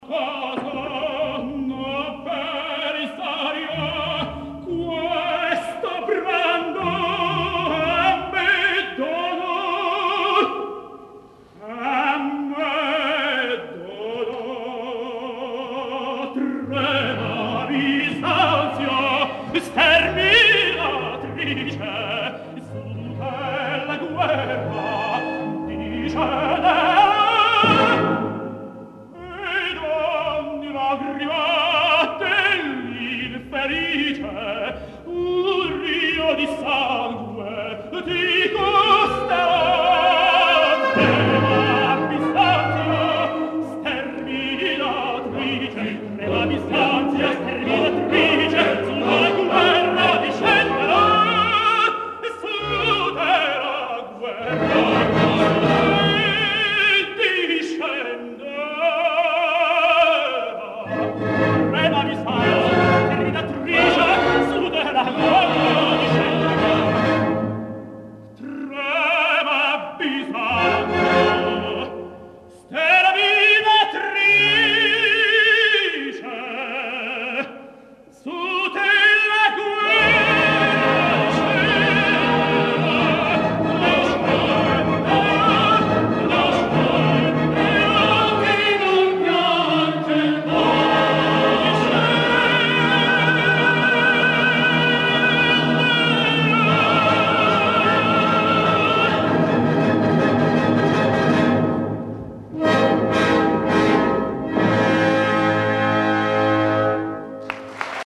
Грац, 1997 г.